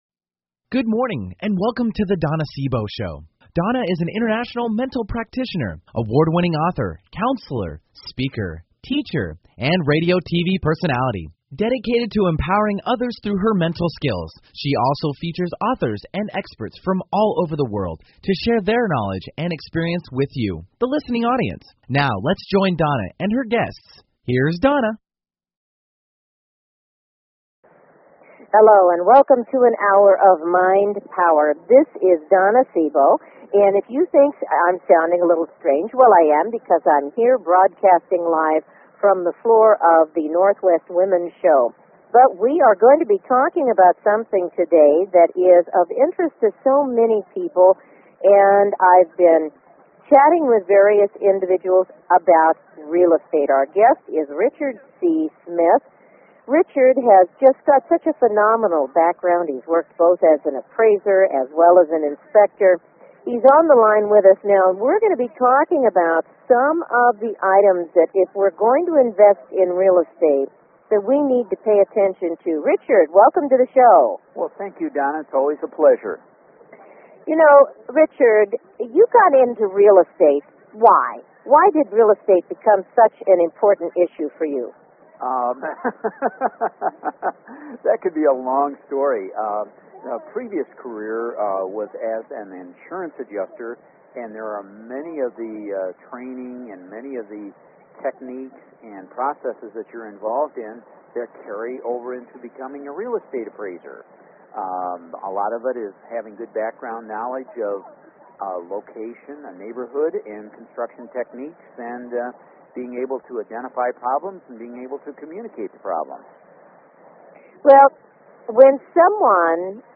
Live broadcast from the floor of the NW Women's Show in Seattle, Washington. This is a very special event that has a lot of excitement and I'll be sharing some of it with you while I am there.